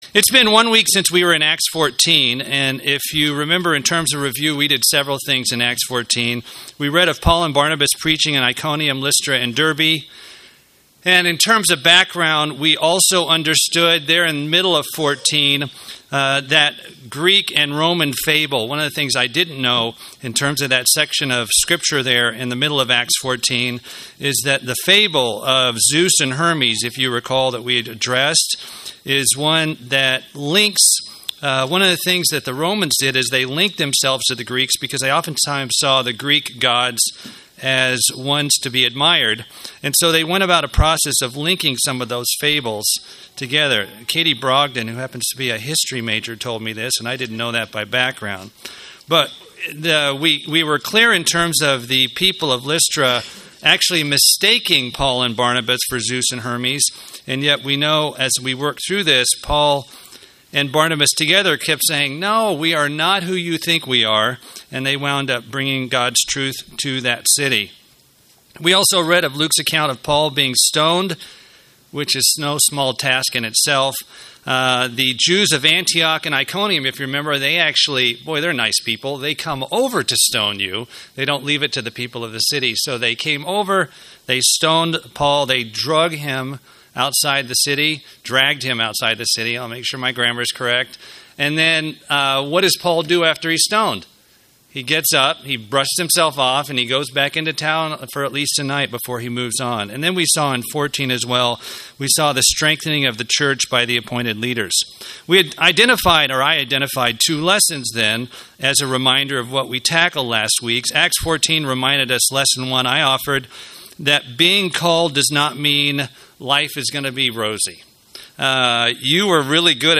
Given in Atlanta, GA
Among the important events recorded there is the apostles discussion on the issue of physical circumcision for the Gentile Christians. UCG Sermon Studying the bible?